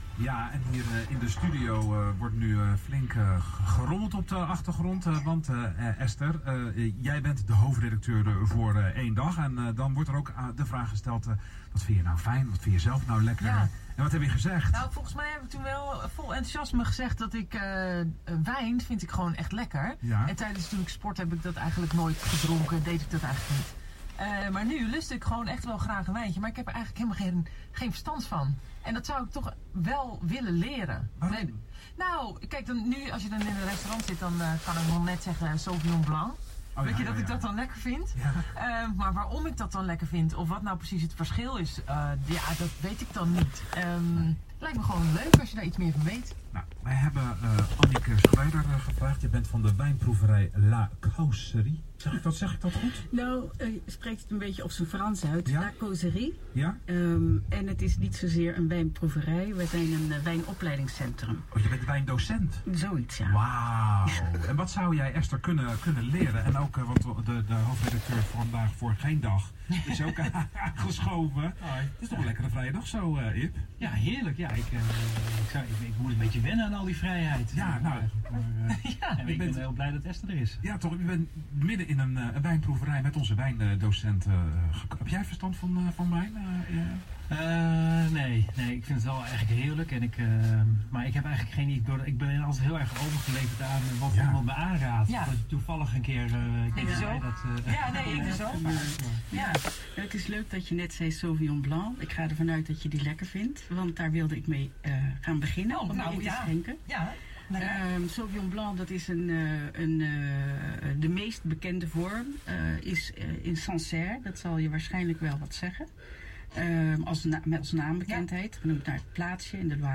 wijnproeverij met Esther Vergeer op Radio Rijnmond